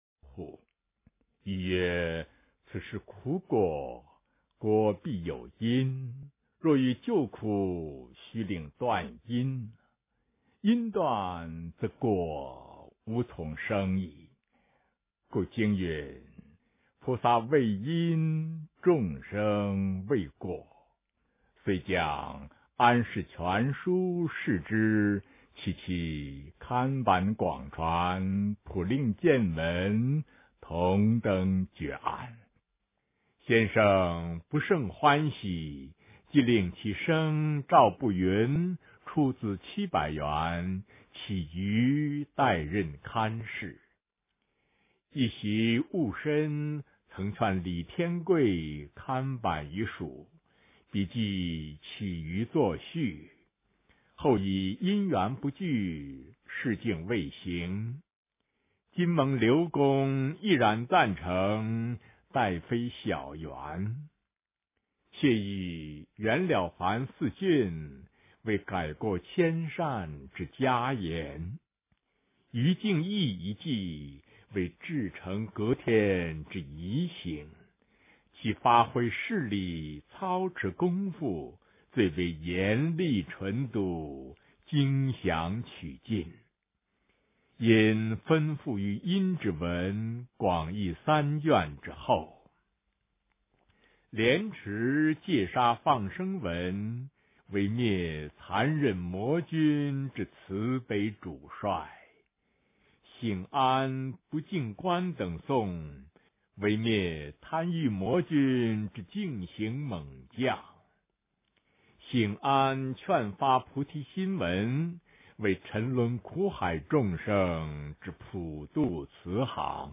《印光法师文钞》之 弘化人心(下)3 - 诵经 - 云佛论坛